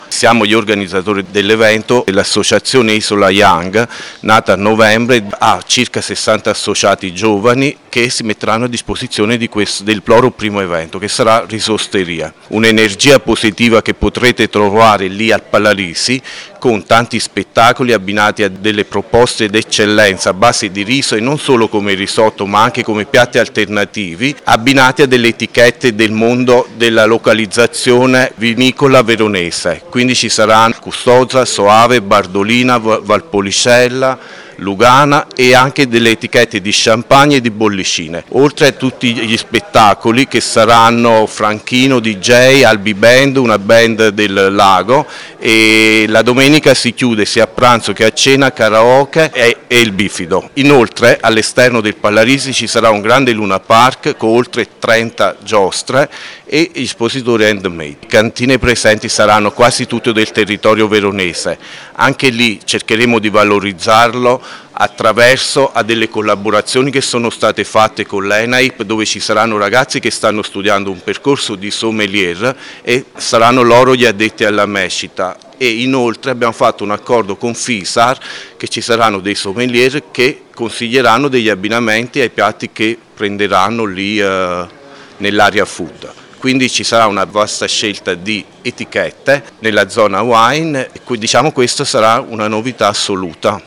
Qui di seguito le interviste raccolte nella giornata di presentazione
Antonio Ruotolo, consigliere del comune di Isola della Scala con delega alle manifestazioni